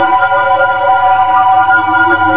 Resonance.ogg